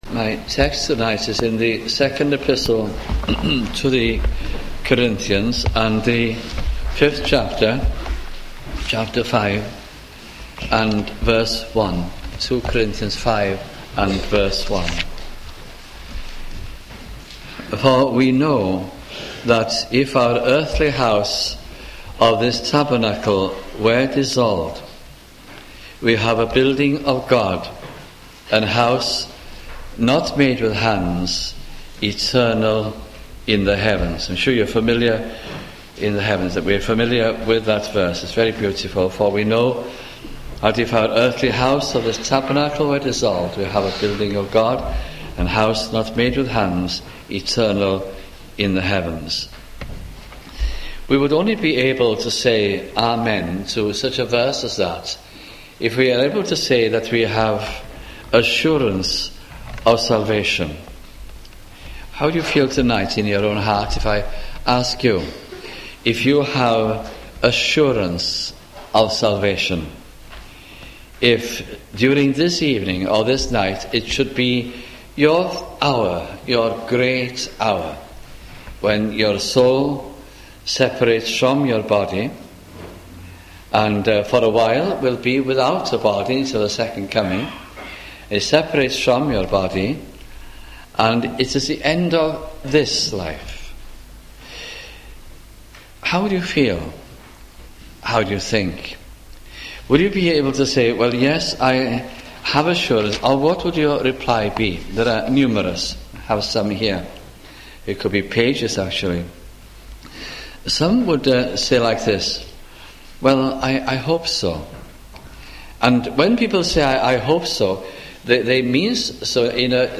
» 2 Corinthians Gospel Sermons